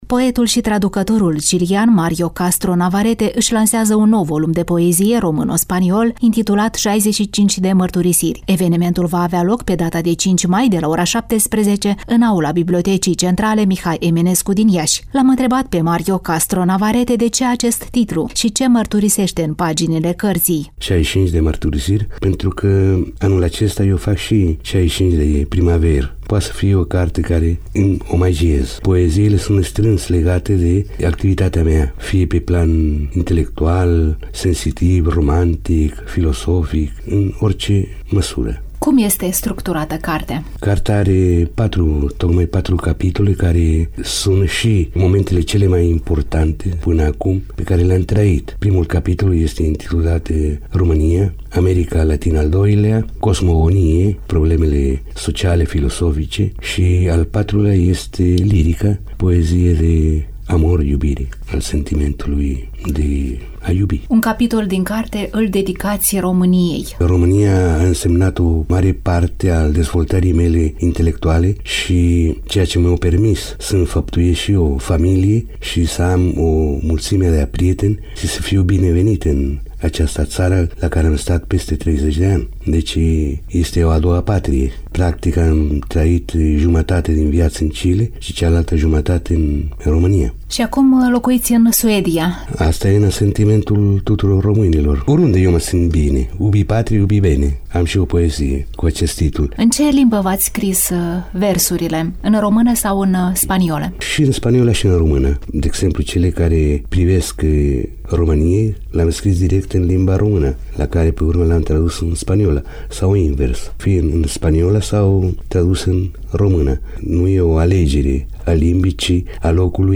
Prima pagină » Rubrici » Reportaj cultural » Lansare inedită de carte